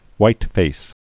(wītfās, hwīt-)